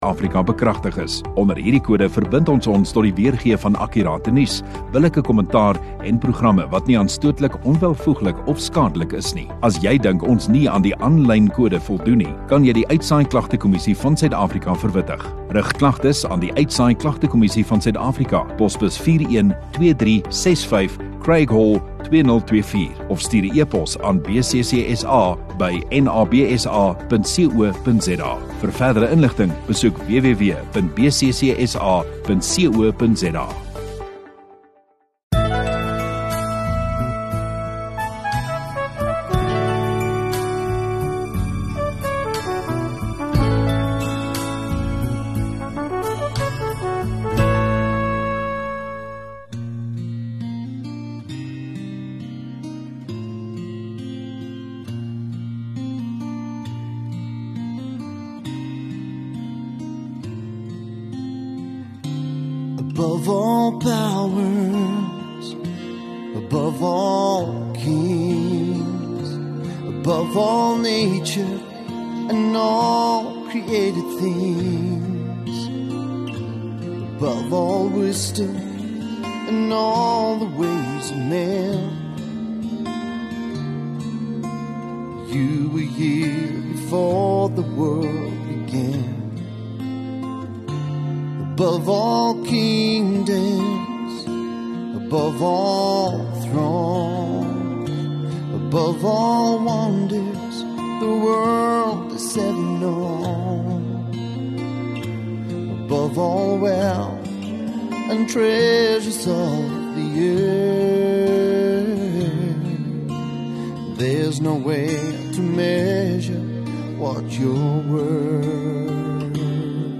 12 Apr Sondagaand Erediens